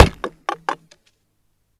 Arrow_impact2.L.wav